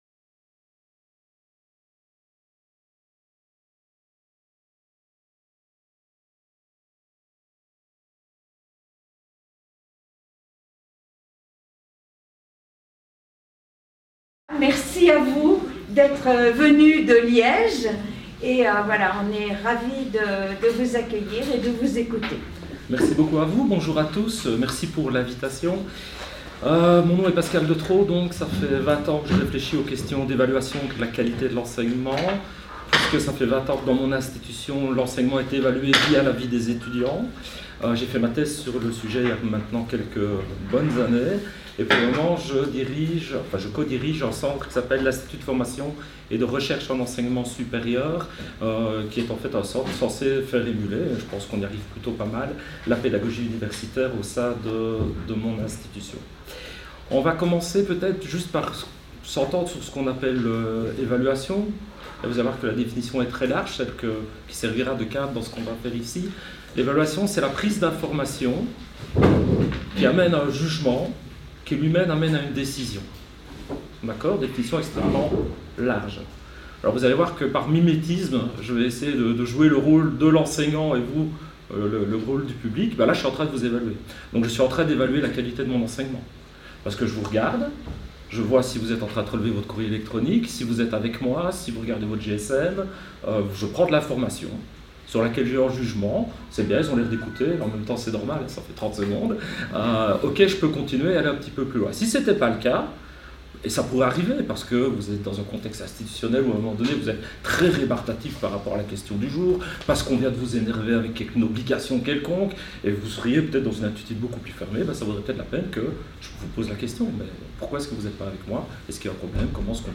conférence